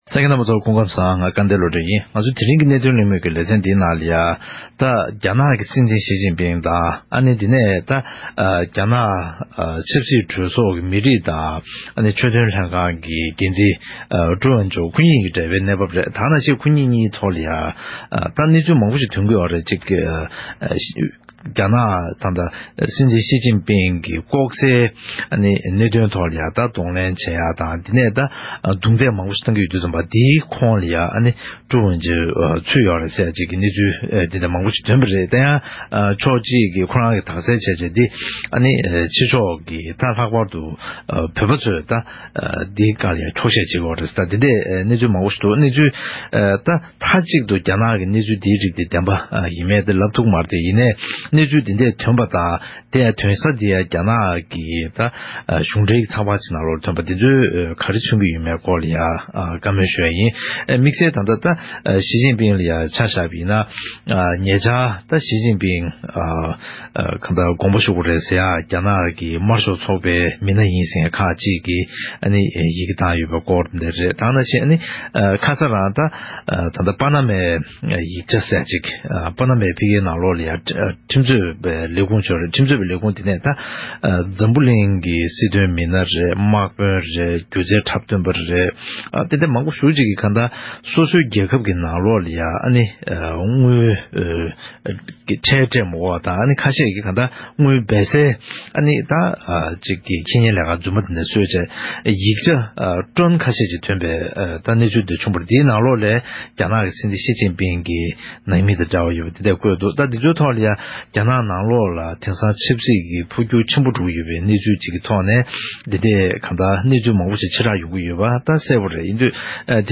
༄༅། །ཐེངས་འདིའི་གནད་དོན་གླེང་མོལ་གྱི་ལེ་ཚན་ནང་། རྒྱ་ནག་གི་སྲིད་འཛིན་ཞི་ཅིན་ཕིང་དང་། རྒྱ་ནག་ཆབ་སྲིད་གྲོས་ཚོགས་ཀྱི་མི་རིགས་དང་ཆོས་དོན་ལྷན་ཁང་གི་འགན་འཛིན་ཊུ་ཝེ་ཆུན་གཉིས་དང་འབྲེལ་བའི་སྐོར་ལ་གླེང་མོལ་ཞུས་པ་ཞིག་གསན་རོགས་གནང་།